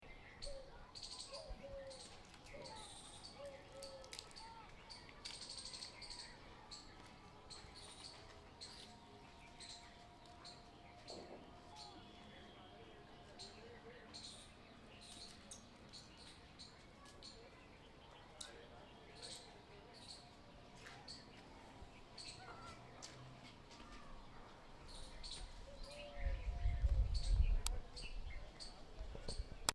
oiseaux.mp3